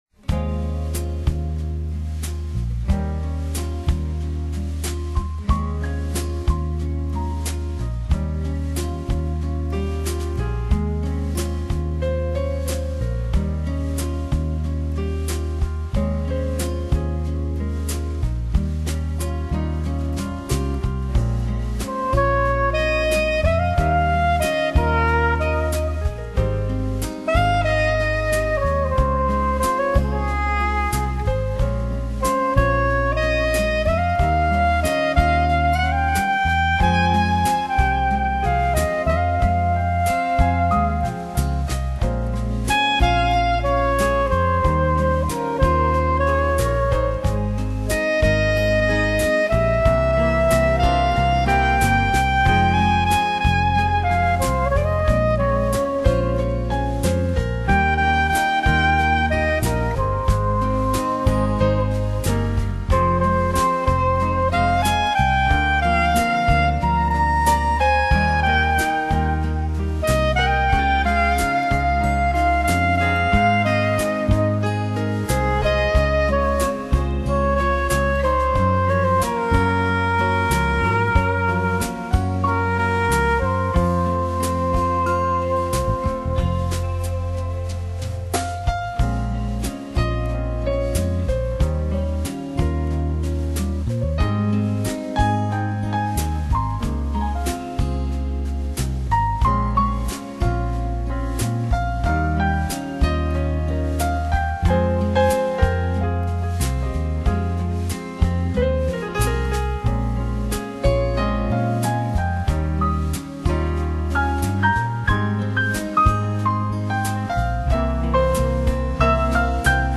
Genre: Jazz/Smooth Jazz Instrumental